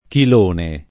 [ kil 1 ne ]